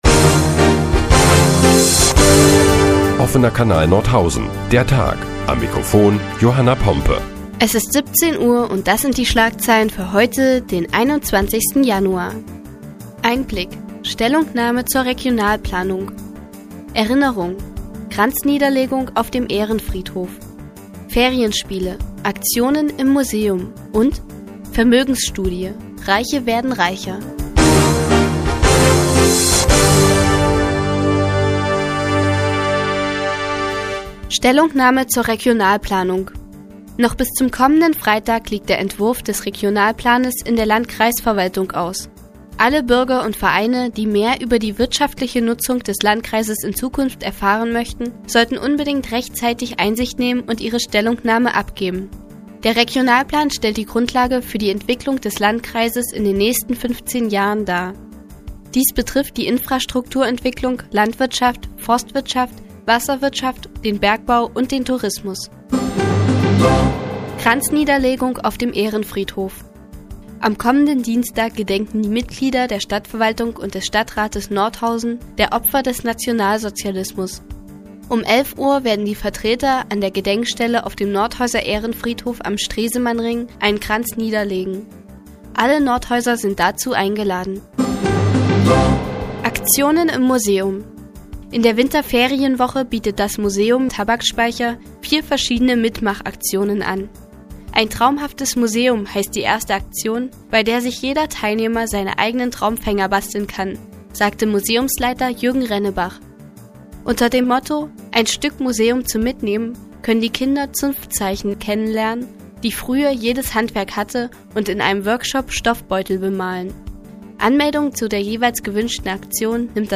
Die tägliche Nachrichtensendung des OKN ist nun auch in der nnz zu hören. Heute geht es unter anderem um den Entwurf des Regionalplanes und Ferienspiele im Museum.